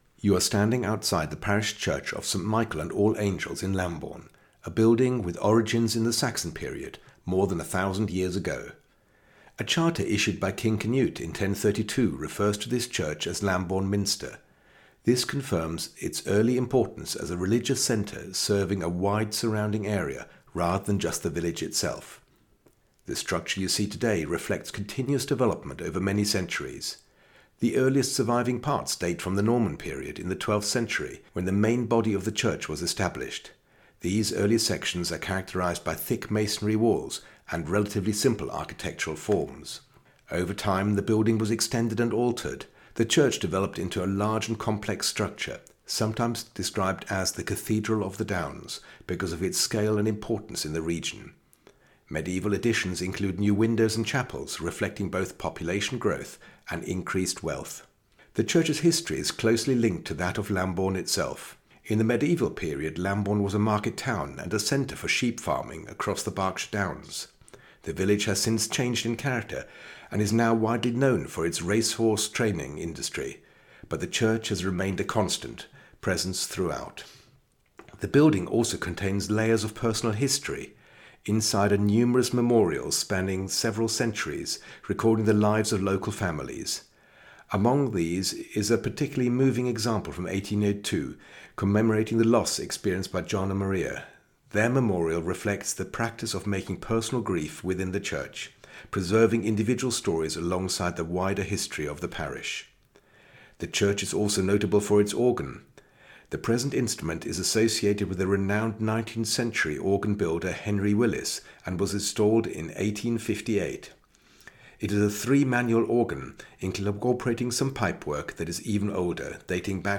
Scan the code and you are taken straight to a short, three minute audio guide.
Just a clear human voice explaining what you are looking at, as if a knowledgeable local guide were standing beside you.